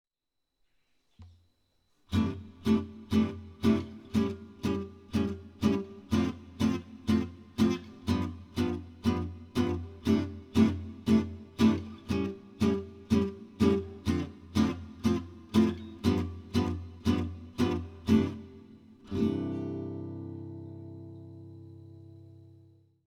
Jazz Guitar
Backing Track 120bpm